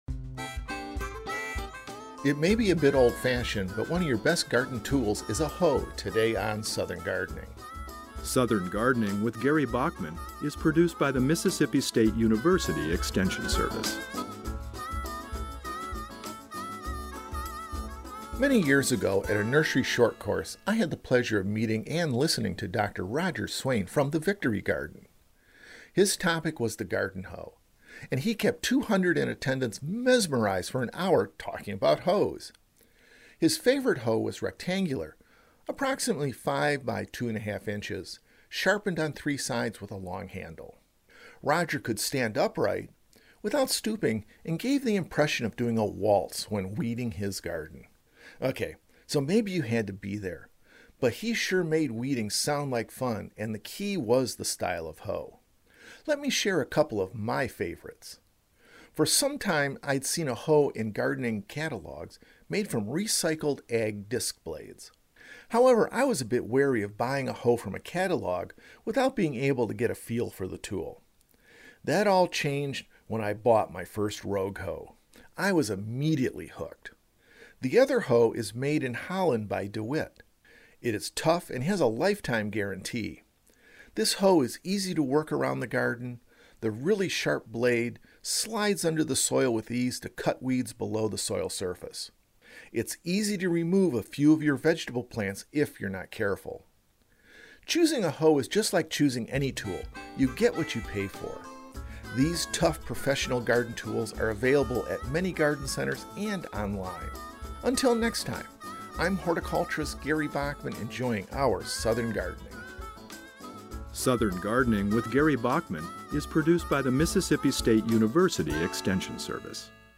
Ornamental Horticulture Specialist